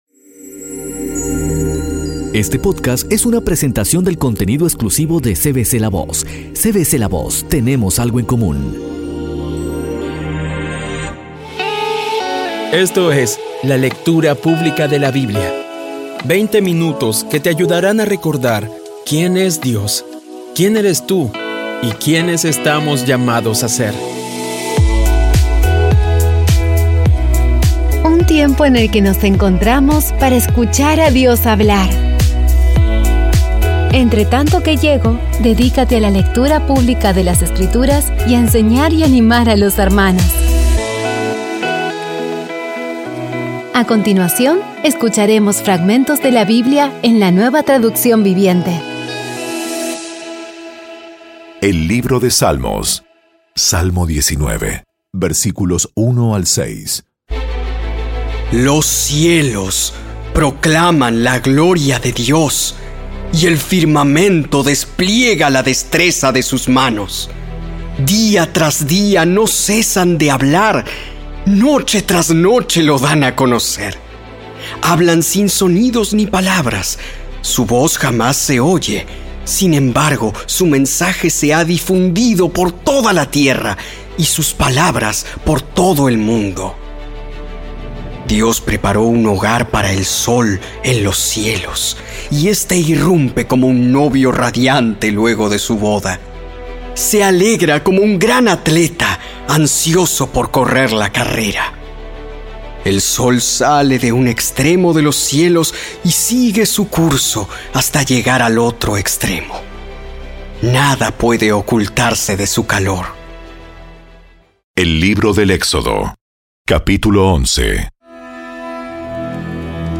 Audio Biblia Dramatizada Episodio 35